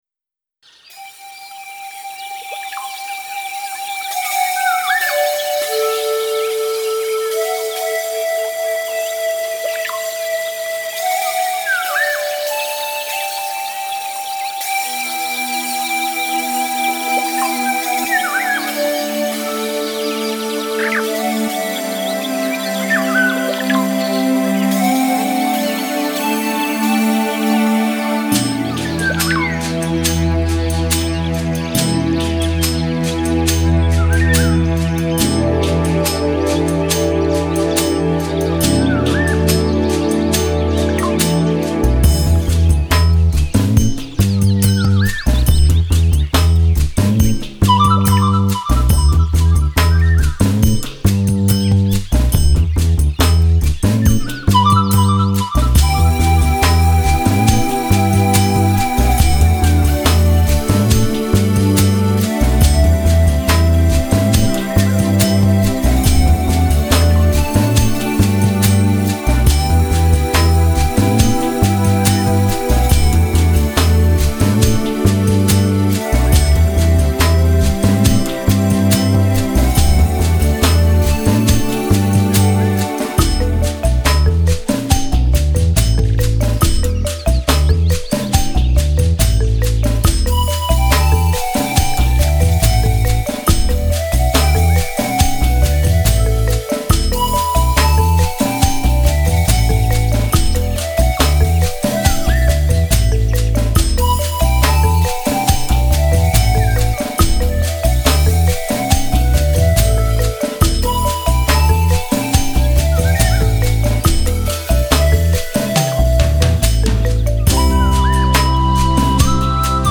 [专辑\合辑] [22/6/2012]自然、清新、愉快、有氧。
清新、自然、宜人、舒适、和谐等一应俱全。
自然地接的音乐-鸟声水流声，都在这里很好听。